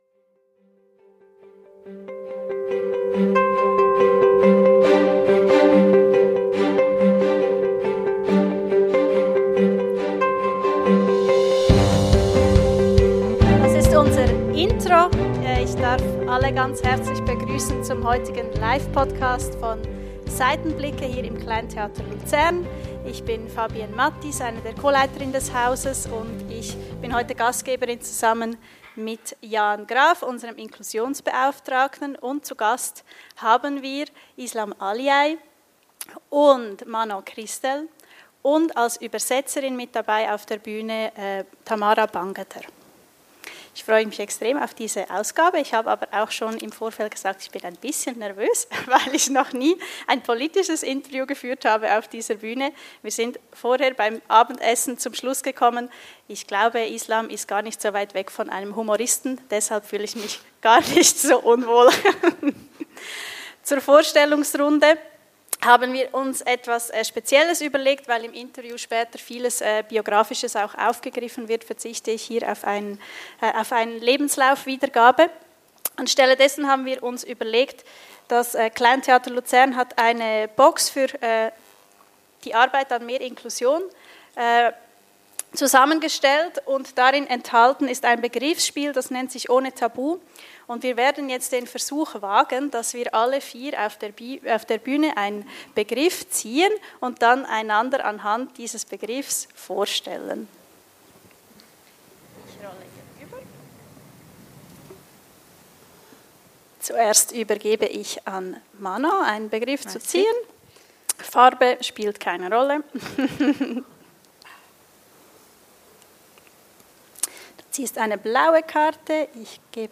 Eine inspirierende, ehrliche und humorvolle Live‑Folge – direkt von der Bühne.